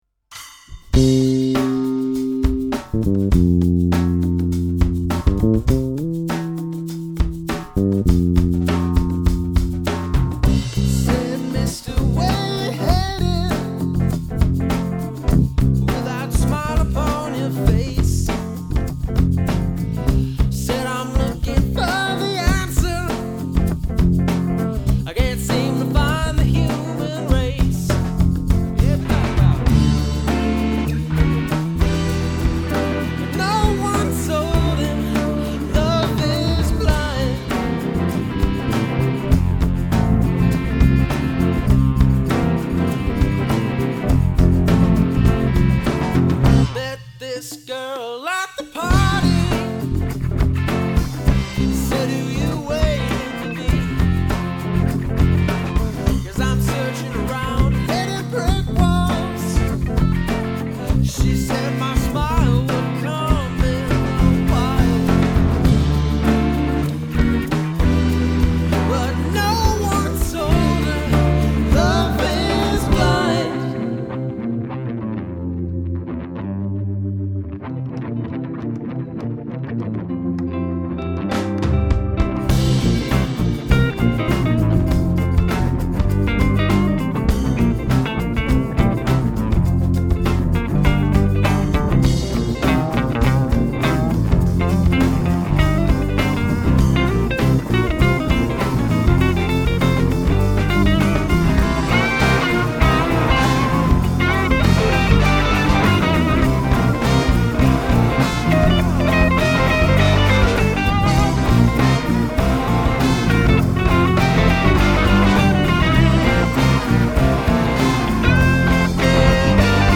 Rock & Roll
Prog rock
Blues